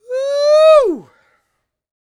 C-YELL 1101.wav